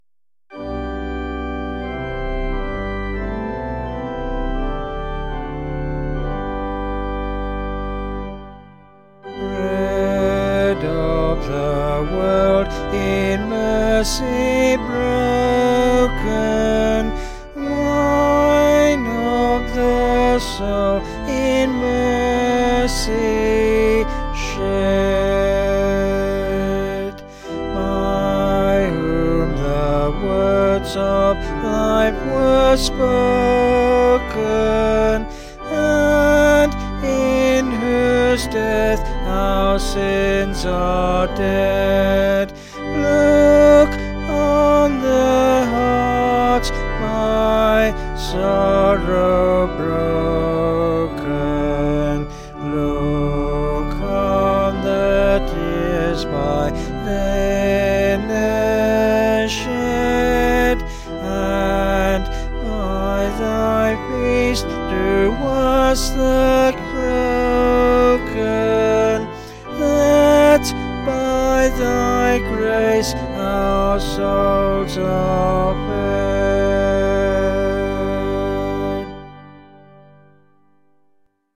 Vocals and Organ   704.6kb Sung Lyrics